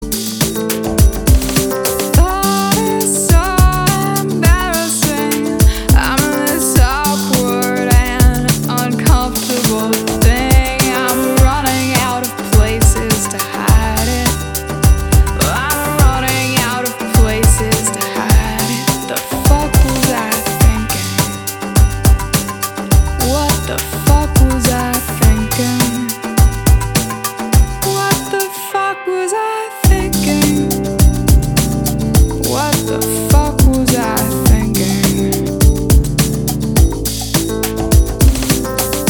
Genre: Chilled/Atmospheric Downtempo
Cheesiness: Not exactly, but sad female vocal
Dance-iness/Intensity: Very low
Tranciness: Low
Progressiveness: Low